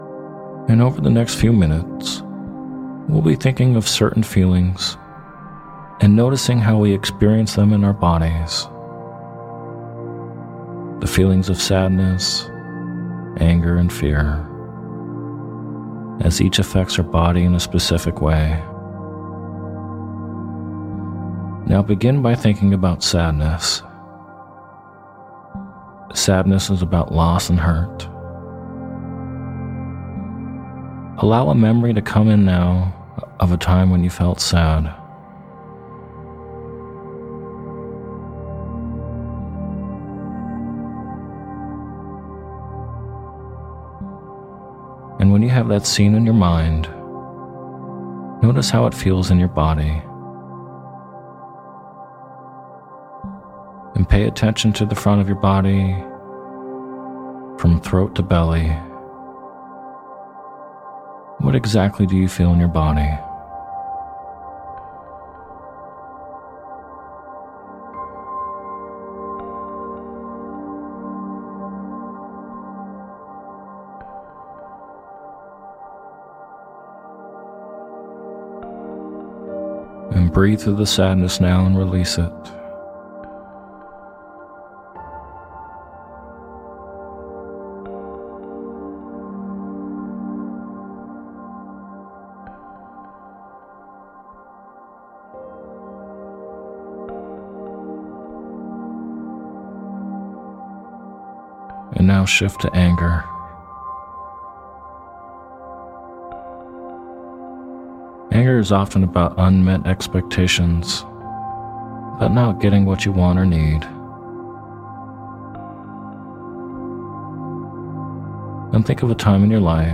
In this hypnotic meditation, you’ll be guided to locate positive and loving feelings in the body.